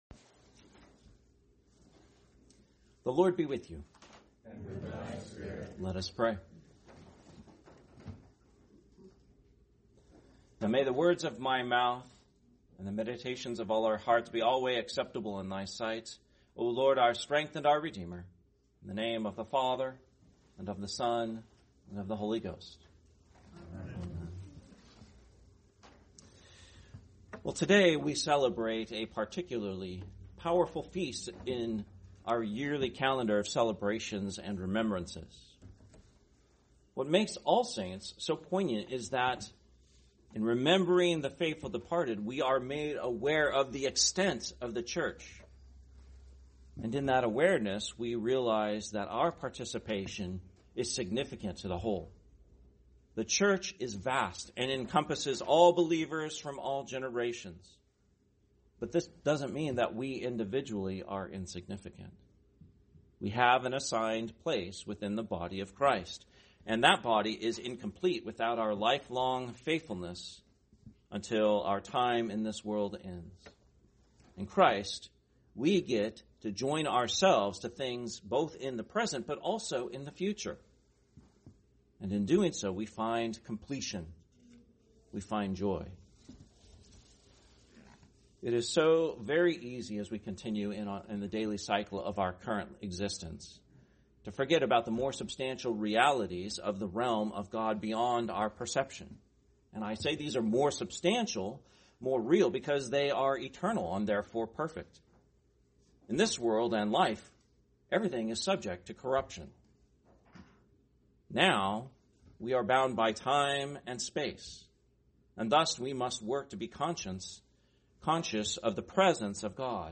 Sunday Morning Worship celebrating the Feast of All Saints, Nov. 2, 2025